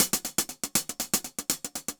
Index of /musicradar/ultimate-hihat-samples/120bpm
UHH_AcoustiHatB_120-02.wav